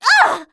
cleric_f_voc_hit_e.wav